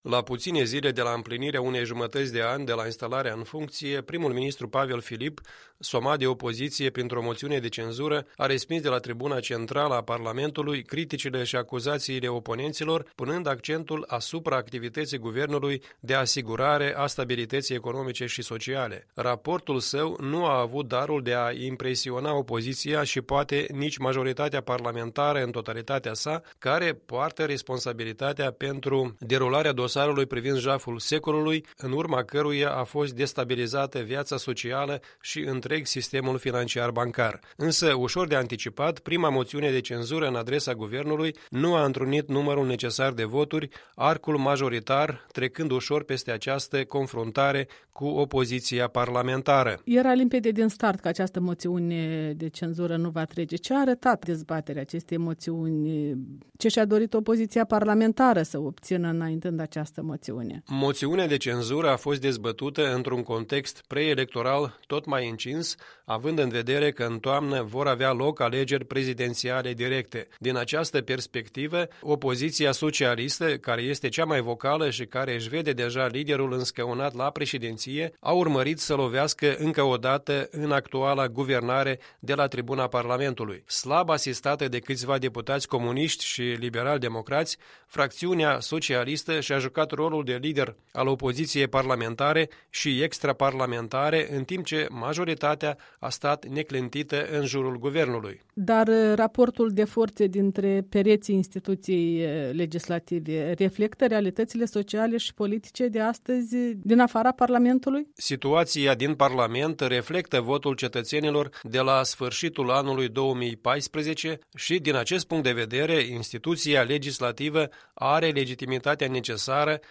Punct de vedere săptămânal la EL.